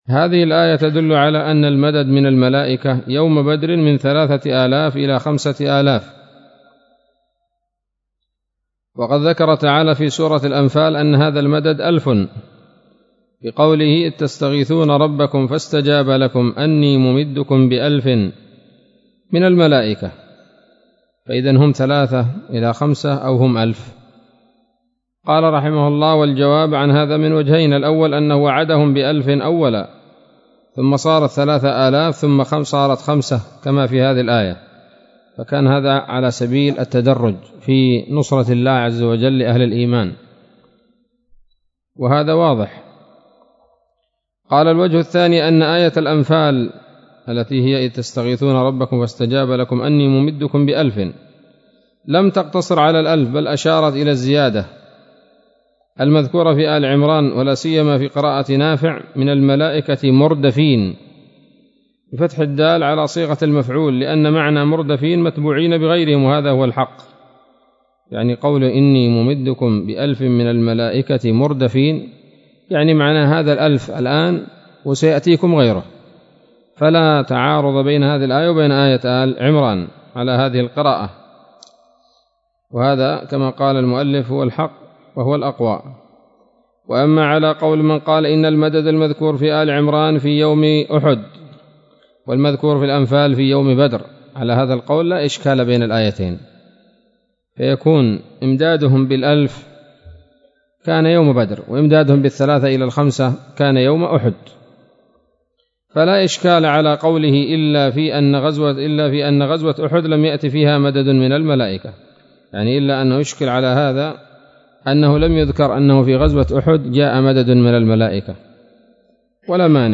تنبيه أول الصوتية انحذف، وهي الآية التي علق عليها الإمام الشنقيطي، وهي قوله:ـ .قوله تعالى: (( إذ تقول للمؤمنين ألن يكفيكم أن يمدكم ربكم بثلاثة آلاف من الملائكة…))